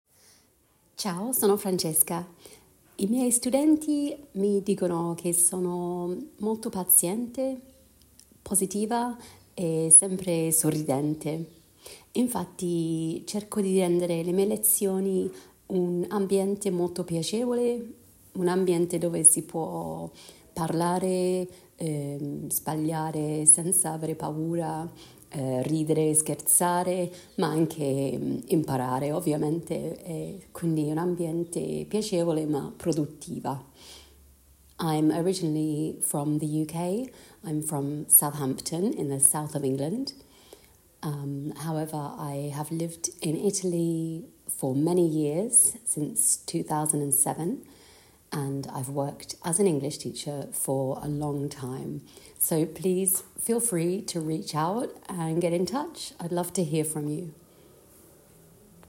Registrare una breve presentazione in Italiano e anche una parte in Inglese, così potenziali studenti potranno sentire la tua voce e il tuo stile di insegnamento.